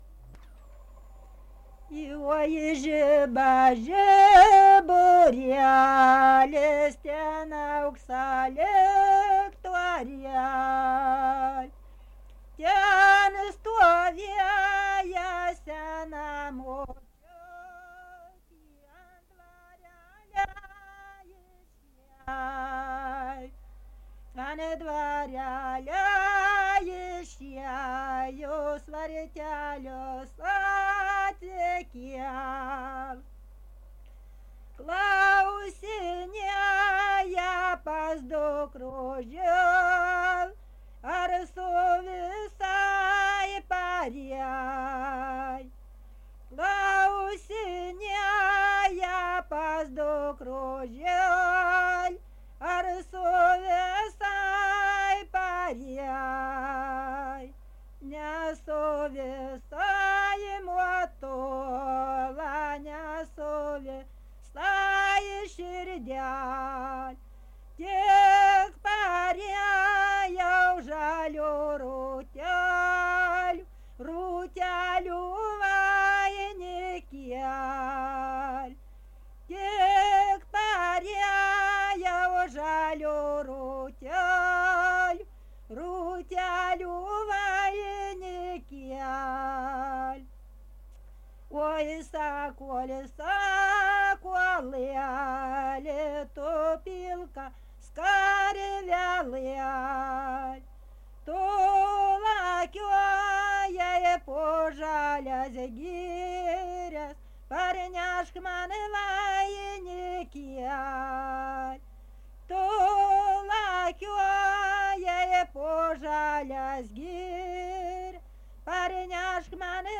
daina, vestuvių
Erdvinė aprėptis Kareivonys
Atlikimo pubūdis vokalinis
Pastabos pirmųjų žodžių ir posmų nesigirdi.